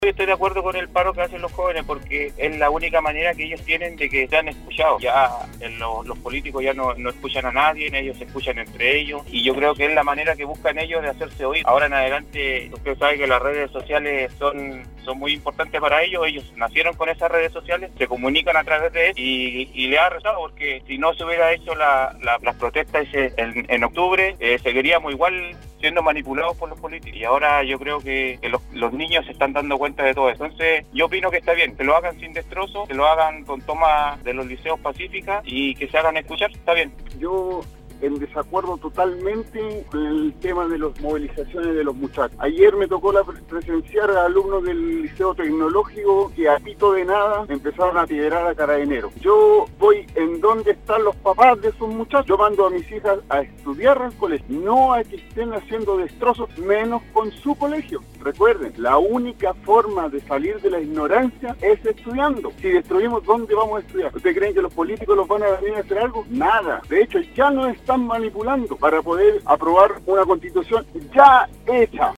La mañana de este miércoles, auditores de Nostálgica participaron en el Foro del Programa Al Día, donde  se refirieron a la toma que se encuentran realizando algunos estudiantes en liceos de la capital regional, como una manera de protestar por las demandas sociales que se vienen solicitando desde el estallido social
Al respecto, se recibieron muchas llamadas y mensajes, donde un gran porcentaje de los participantes recalcaron que no están de acuerdo con esta medida que retrasaría el año escolar que apenas comenzó el pasado 05 de marzo.